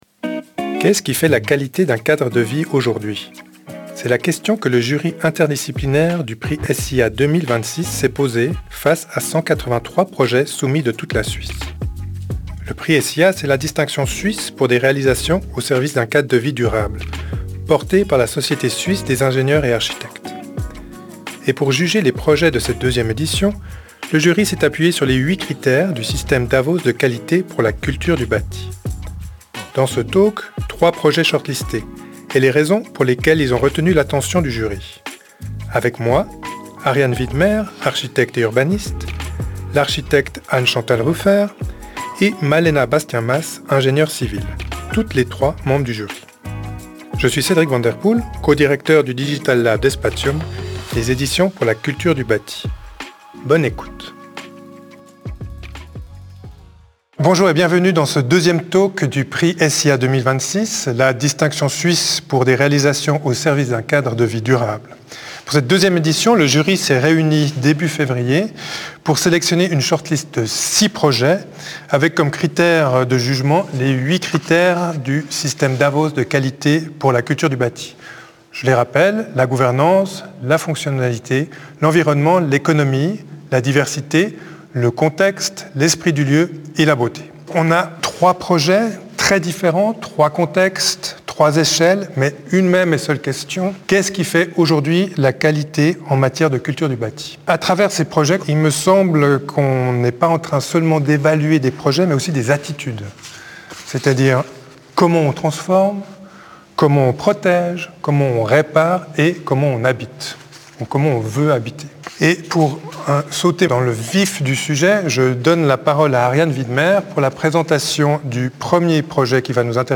Retrouvez l’intégralité de la discussion dans cette vidéo.